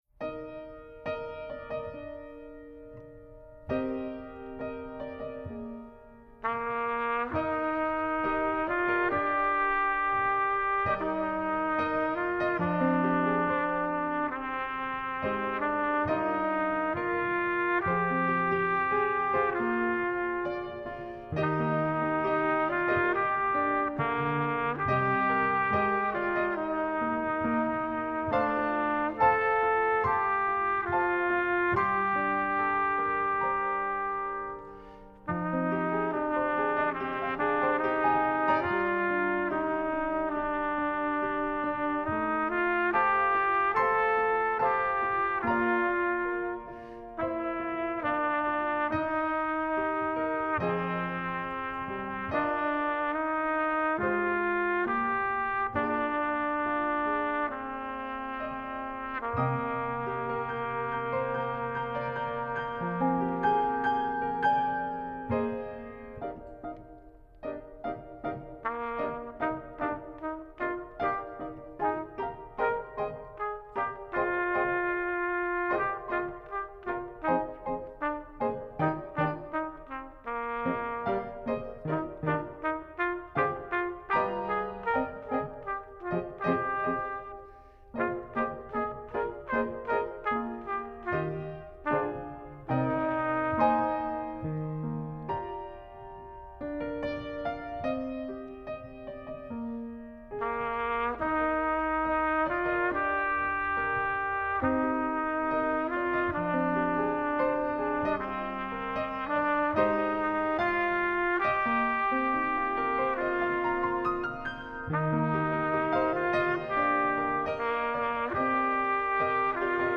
Style: Lyrical/Technical
Bb Trumpet and Piano